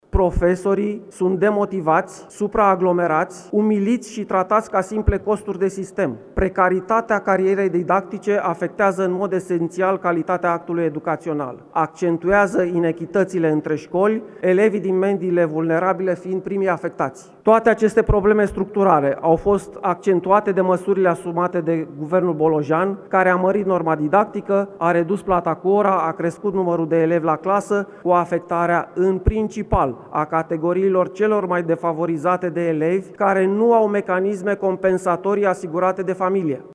Senatorul AUR, Laurențiu Plăeșu: „Precaritatea carierei didactice afectează în mod esențial calitatea actului educațional”